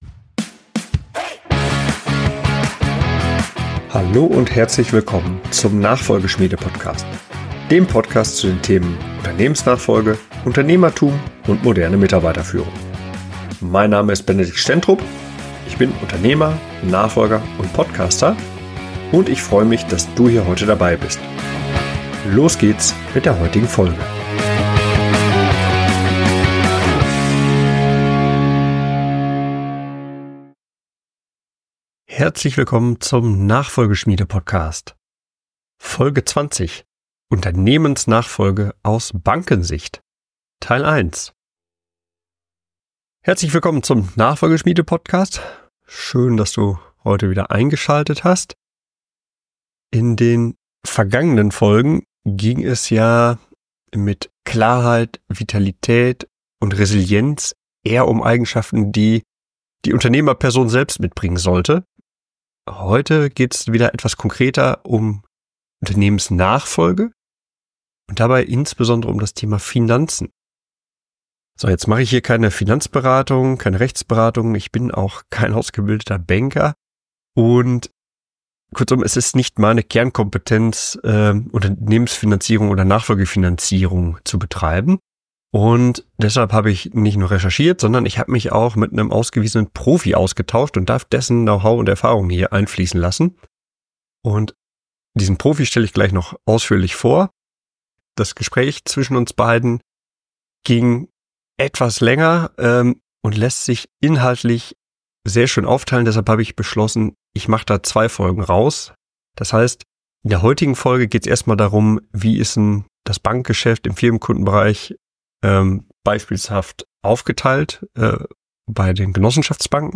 Ich habe mich hierzu mit einem Experten für das Firmenkundengeschäft unterhalten und der hat mir verraten, mit welchem Blick eine Bank auf den Nachfolgeprozess eines Firmenkunden schaut.